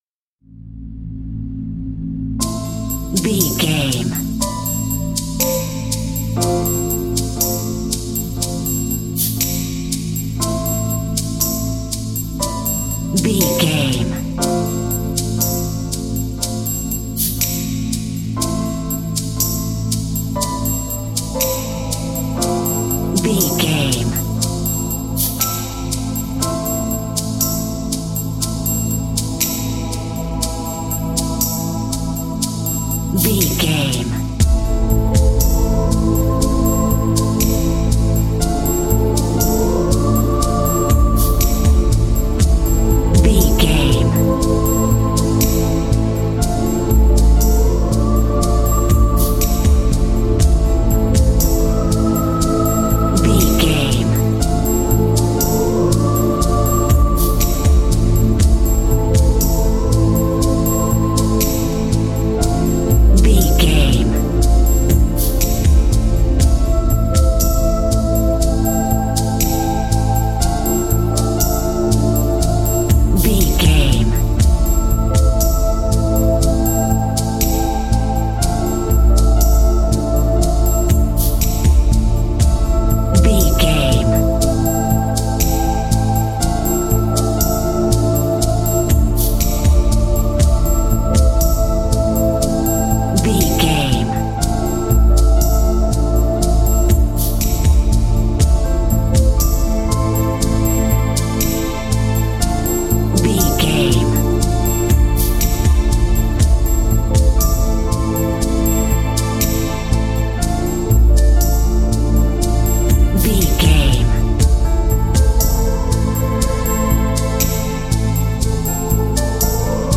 Aeolian/Minor
Slow
synthesiser
piano
percussion
drum machine
ominous
dark
suspense
haunting
creepy